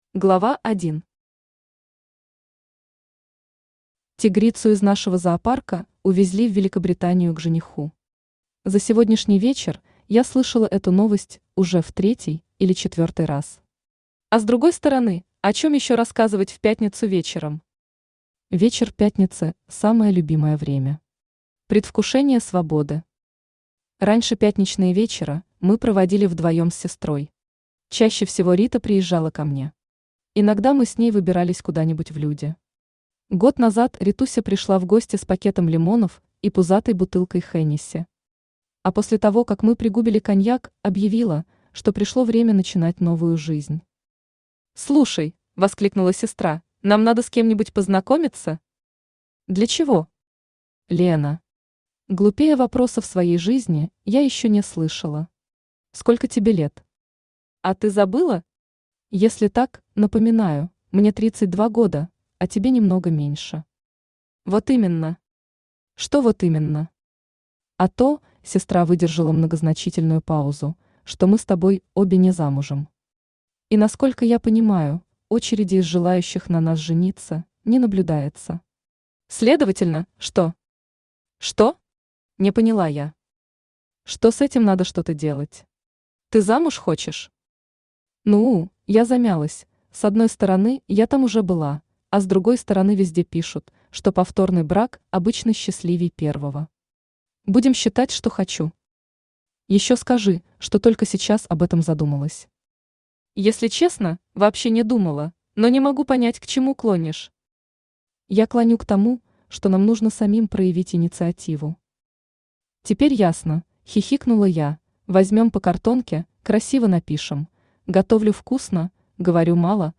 Аудиокнига Новый год с летальным исходом | Библиотека аудиокниг
Aудиокнига Новый год с летальным исходом Автор Оксана Мелякина Читает аудиокнигу Авточтец ЛитРес.